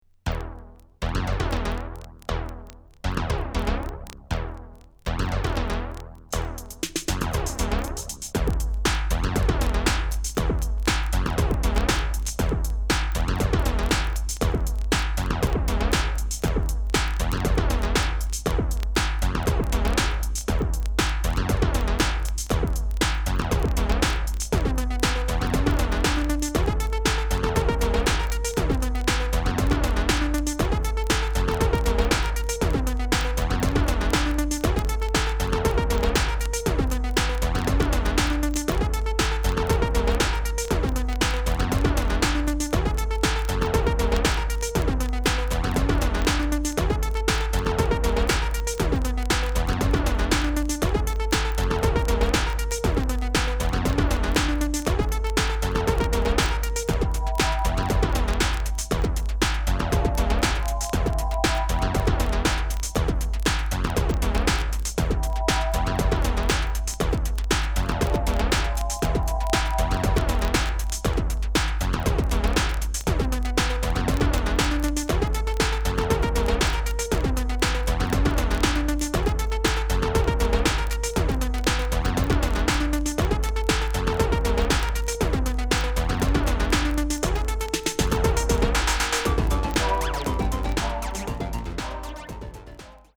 Razor sharp scientific electro tunes.